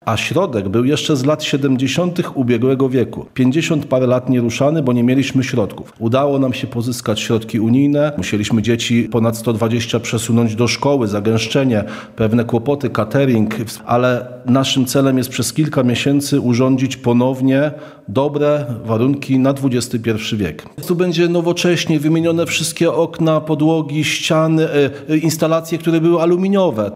– W pierwszym etapie udało się nam pozyskać środki na wykonanie prac na zewnątrz budynku, dzięki czemu wykonaliśmy docieplenie, elewację i wymieniliśmy okna – mówi w rozmowie z Radiem Lublin wójt gminy Trawniki Damian Daniel Baj.